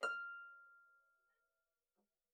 KSHarp_F6_mf.wav